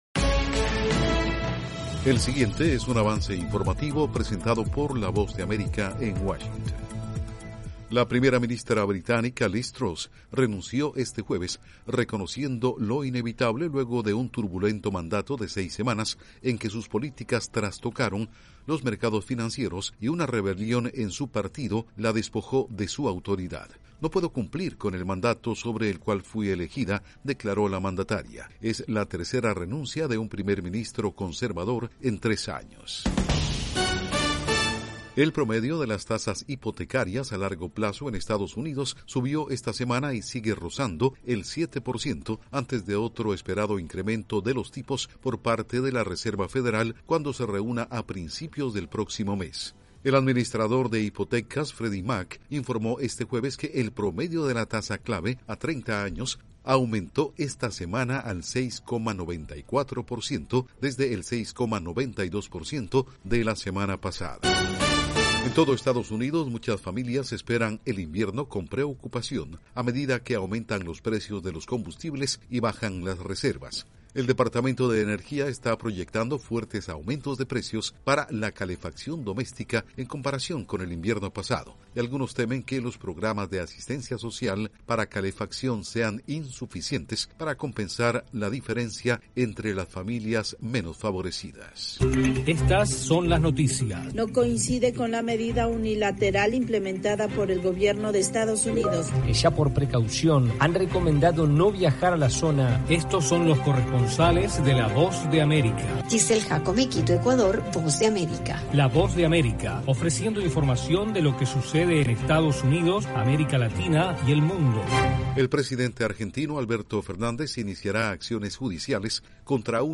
Este un avance informativo de la Voz de América